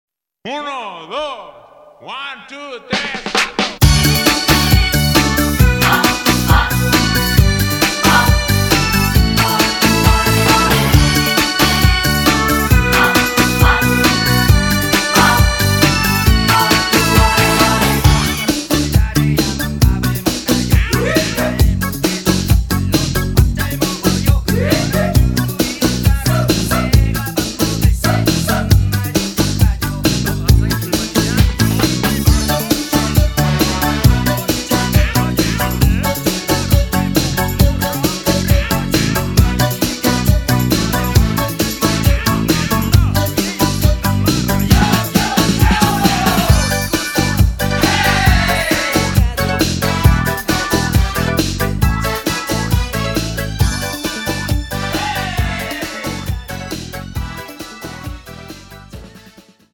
음정 원키 3:13
장르 가요 구분 Voice MR